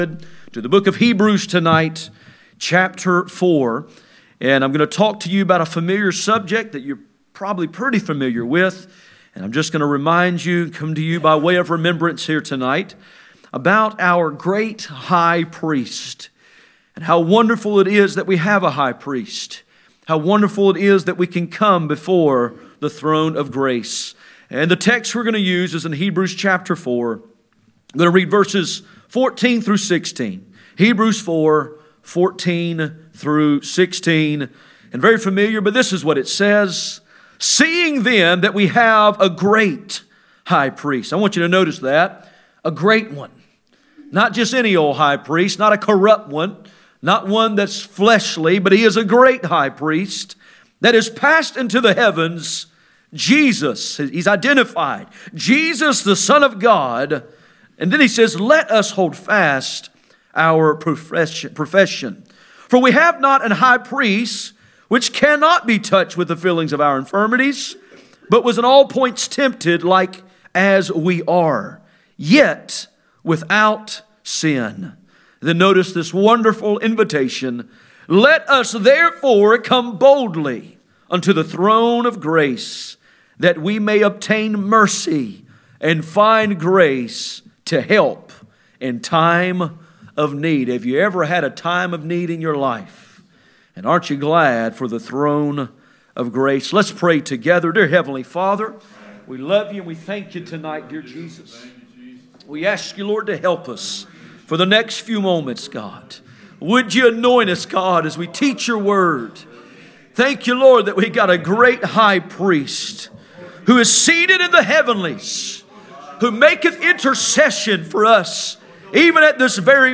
Passage: Hebrews 4:14-16 Service Type: Sunday Evening